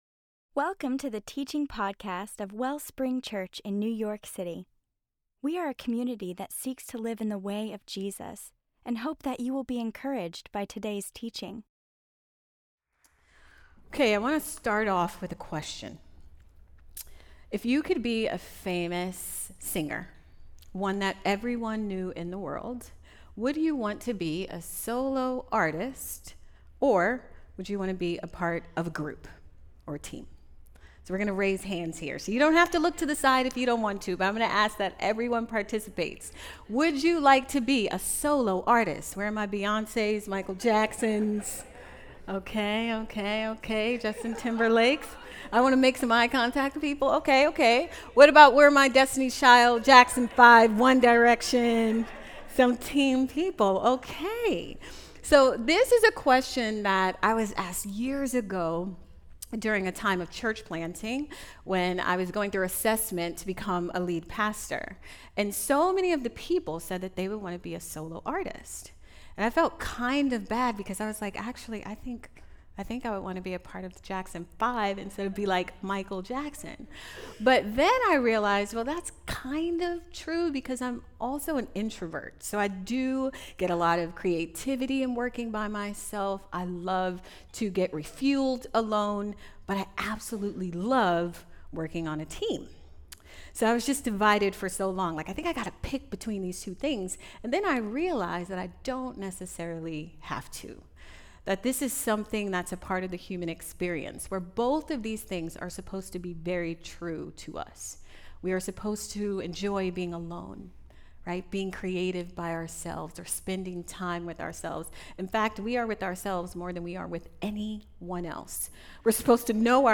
KINGDOM COME: CORPORATE PRAYER